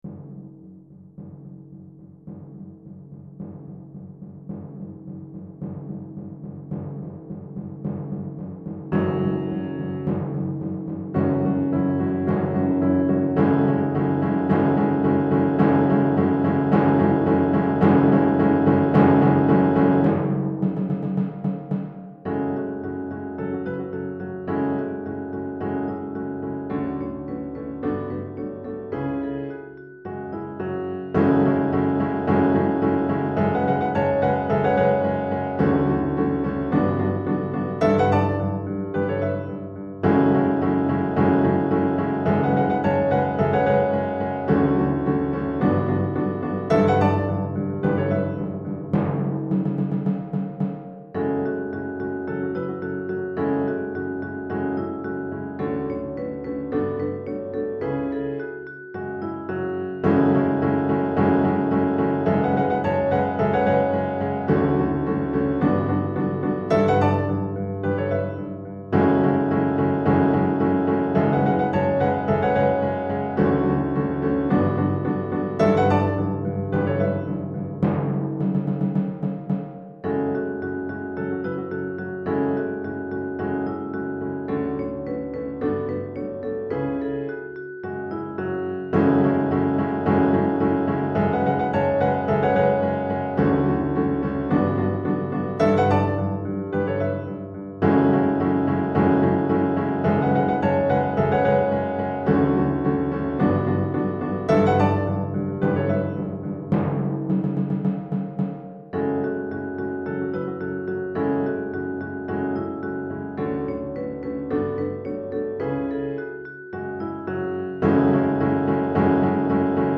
Chorale d'Enfants (8 à 11 ans) et Piano